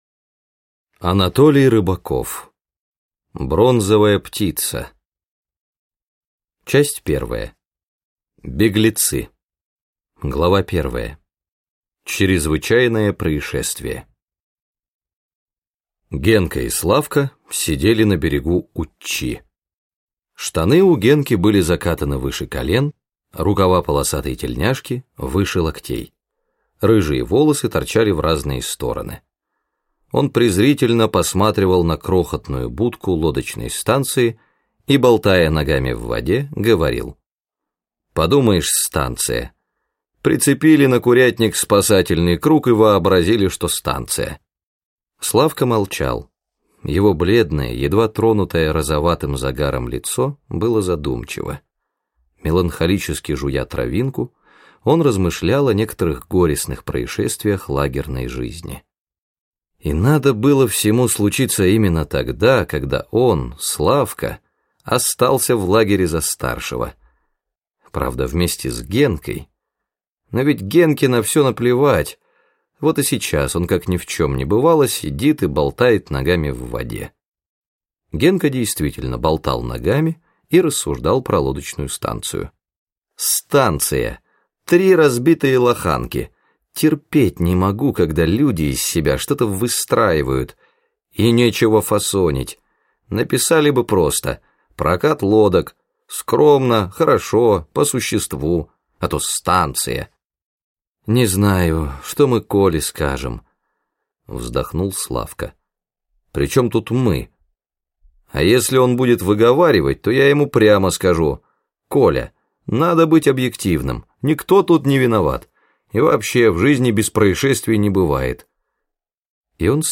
Аудиокнига Бронзовая птица | Библиотека аудиокниг